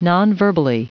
Prononciation du mot nonverbally en anglais (fichier audio)
Prononciation du mot : nonverbally
nonverbally.wav